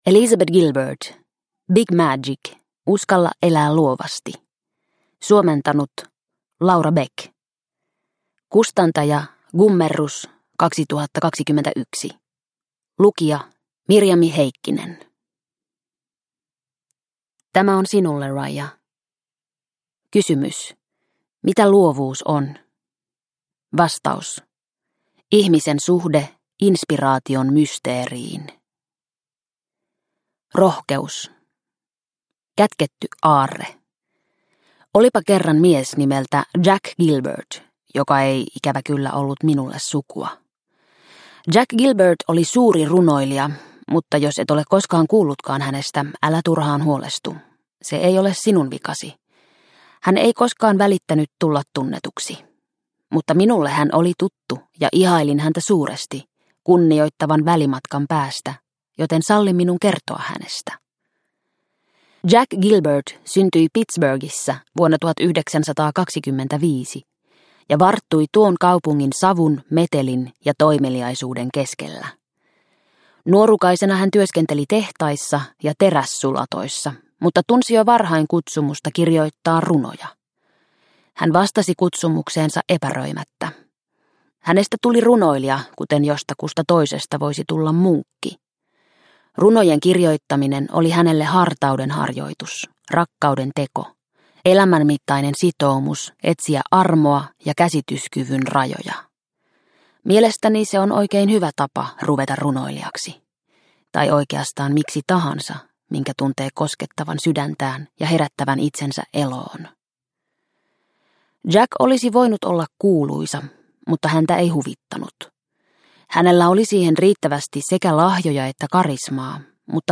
Big Magic – Ljudbok – Laddas ner